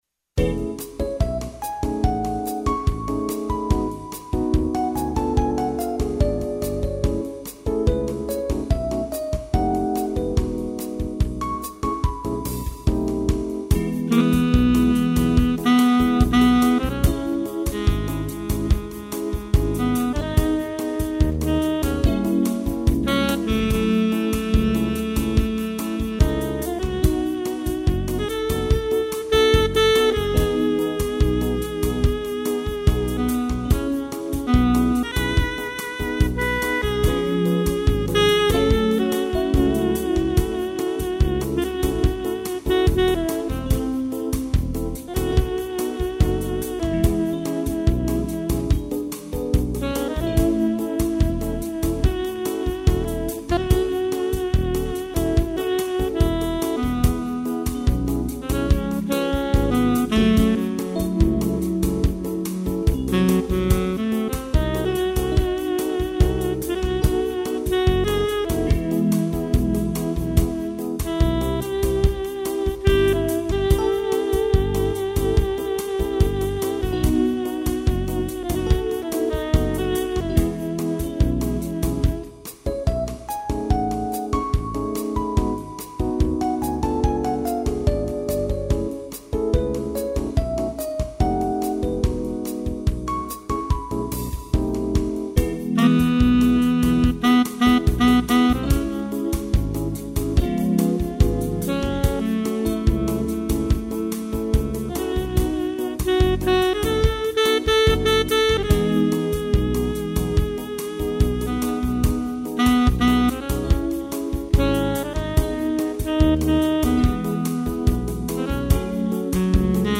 sax
piano